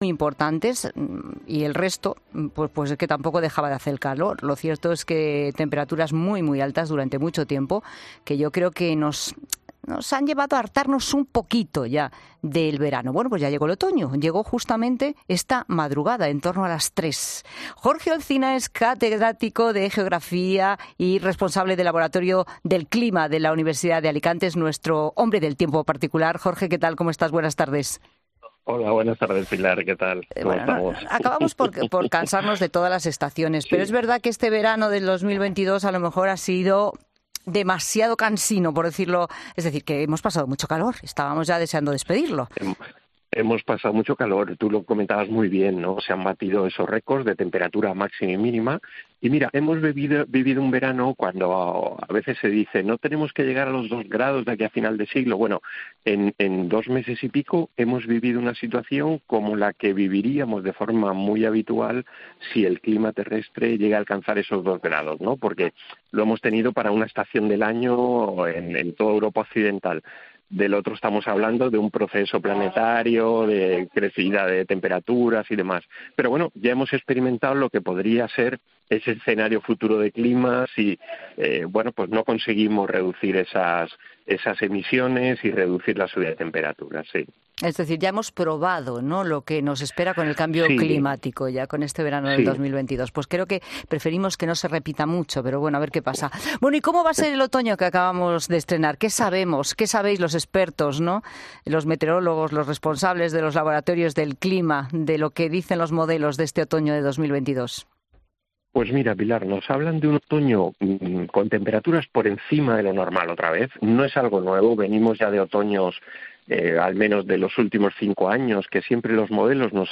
Un meteorólogo predice cómo será el tiempo este otoño: "Existe preocupación"